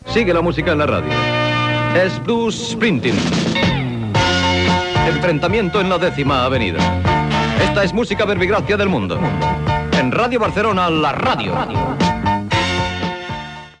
Presentació d'un tema de Bruce Springsteen i identificació de l'emissora.
Musical